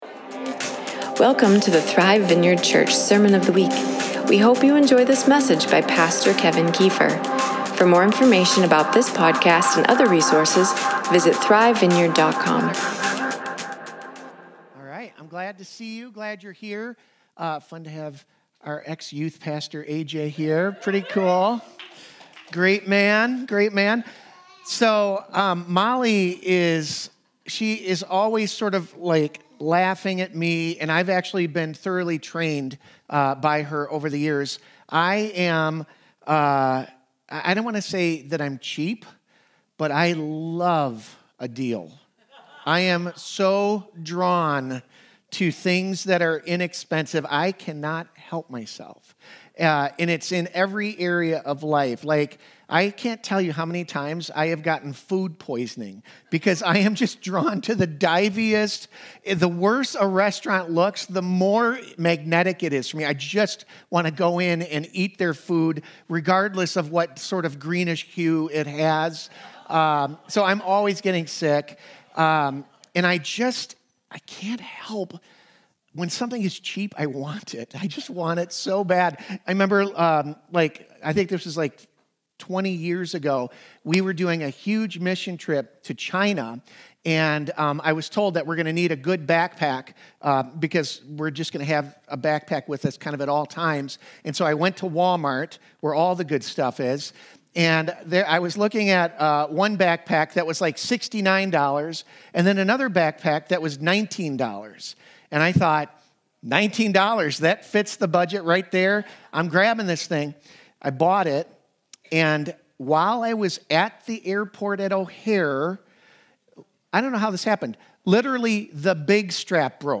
2025 Blessing Blessings Discipleship Sacrifice Sunday Service Jesus never sugarcoated the cost of discipleship.